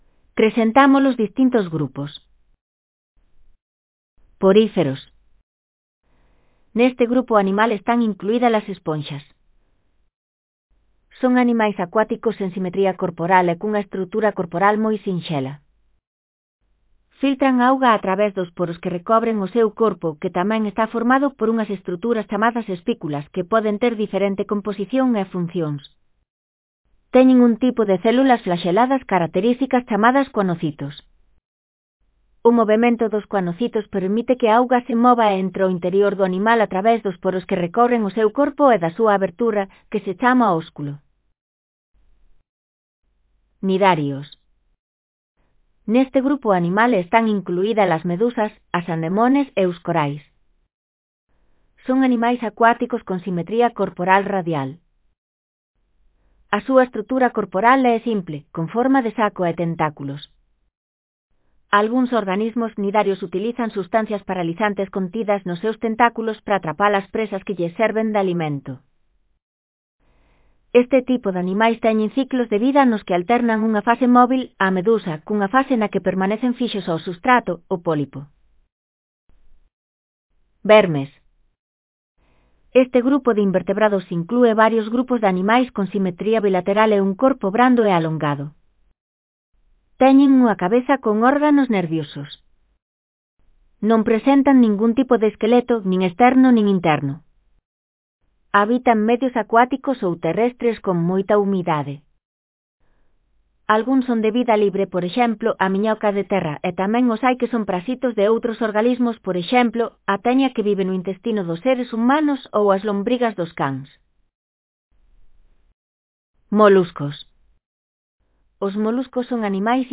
Elaboración propia (proxecto cREAgal) con apoio de IA, voz sintética xerada co modelo Celtia.